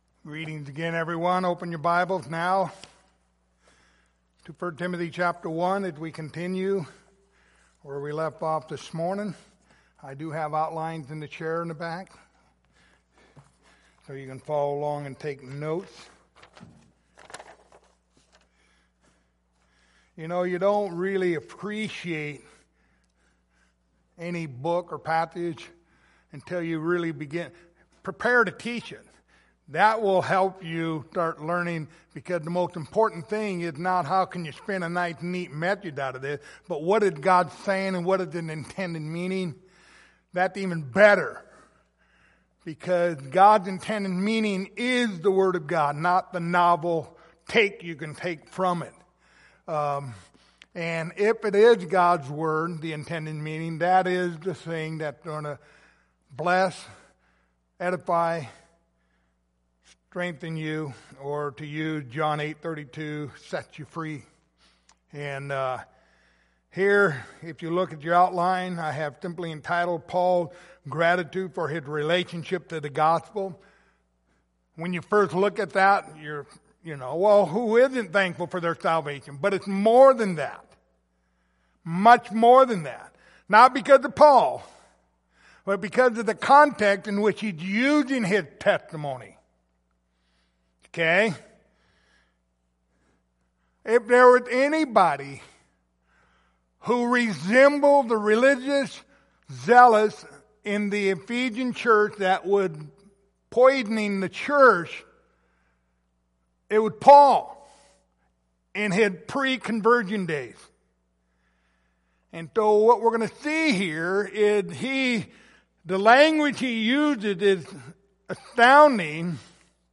Passage: 1 Timothy 1:12-17 Service Type: Sunday Evening